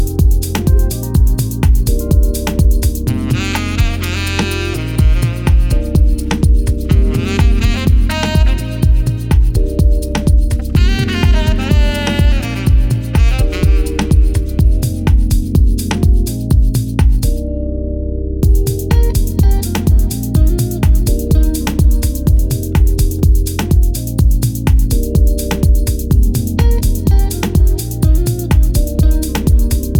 しっとりウォームでムーディーなフィーリングを基調とし、ジャジーな音色も程よくちりばめながら